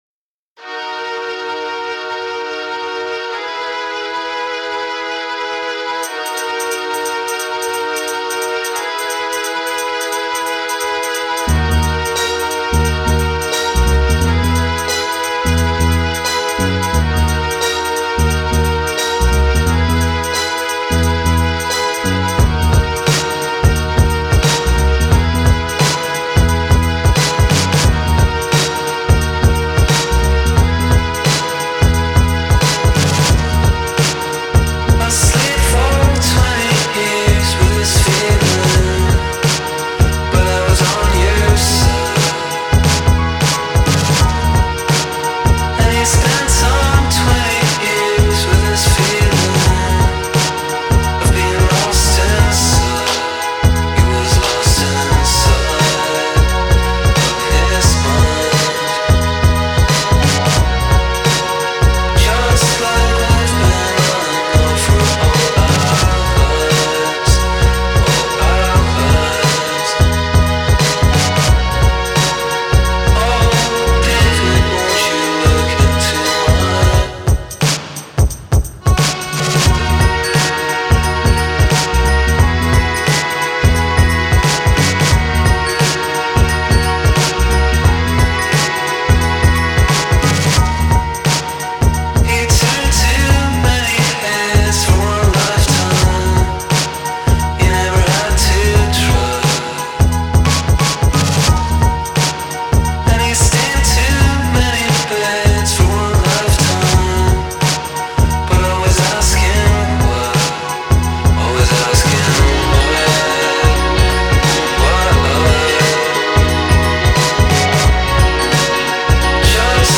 le taciturne canzoni
voce filtrata